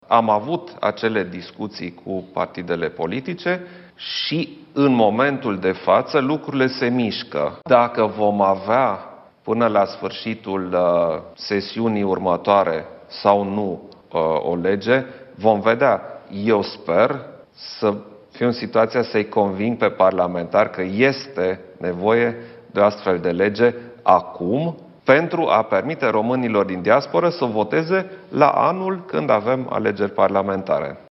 Recent, într-un interviu penstru postul public de televiziune, Președintele a explicat ce a făcut până acum.
12-iun-11-Iohannis-de-la-TVR-despre-legea-votului-prin-corespondenta-.mp3